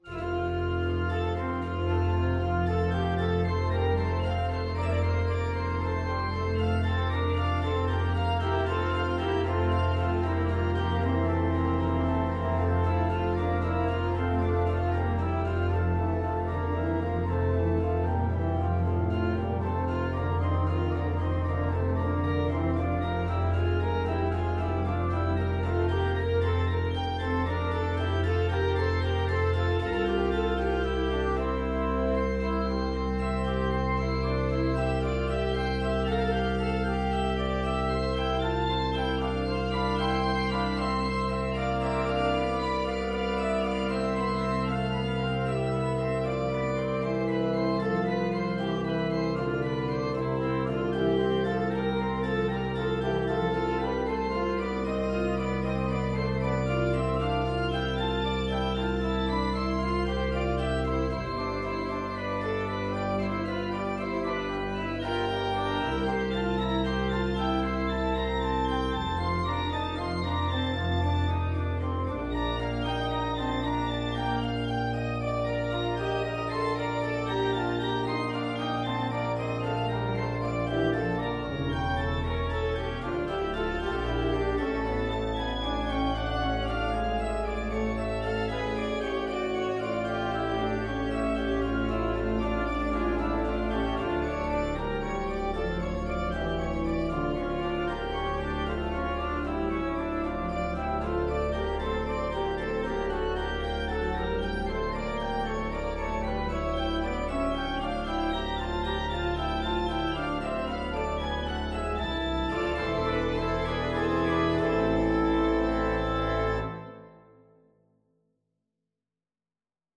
Instrument: Organ
Style: Classical